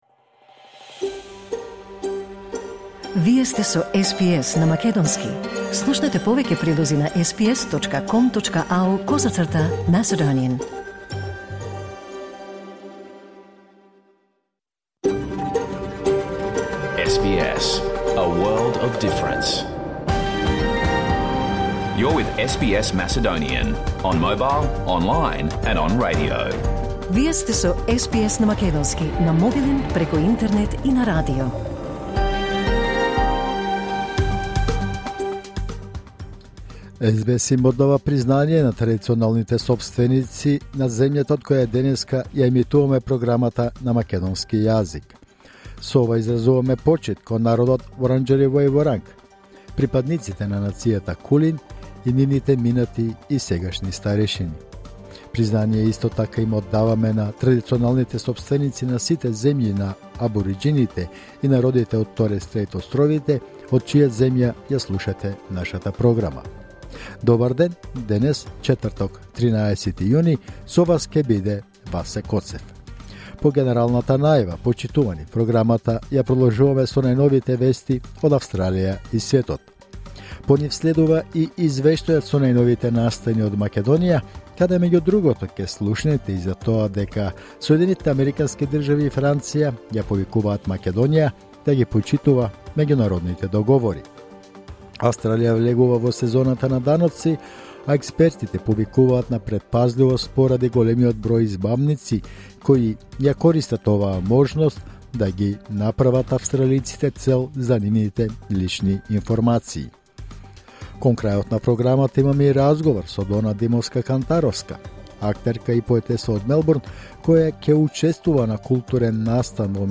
SBS Macedonian Program Live on Air 13 June 2024